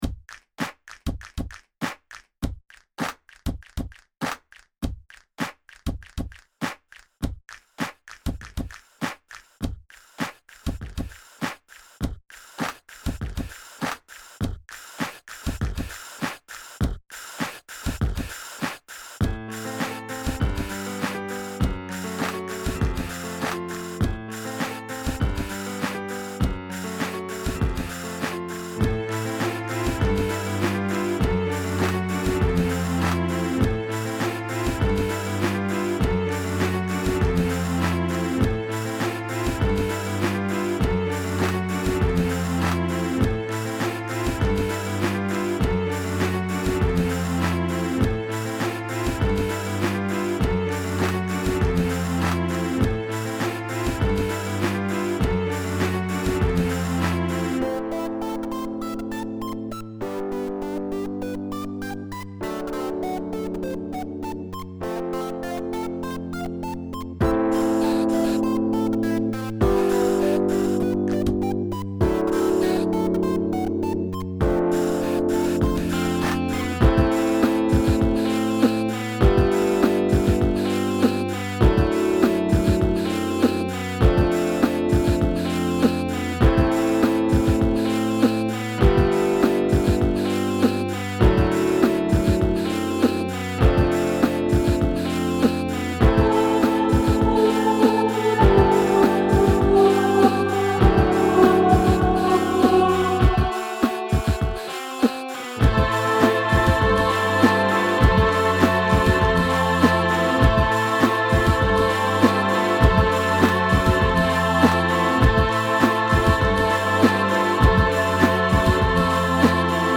Me voilà avec un morceau GarageBand fait avec mon petit clavier et mes petits doigts.
Ah, c'est d'une douceur. :)
Tu as utilisé quoi comme instrument à la fin dont le timbre ressemble à des voix ?